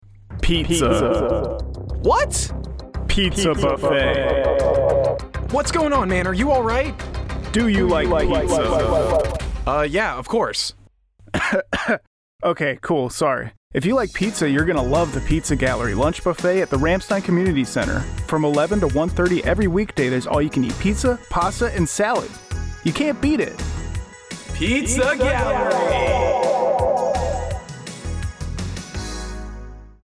Radio Spot - Ramstein Pizza Gallerie Buffet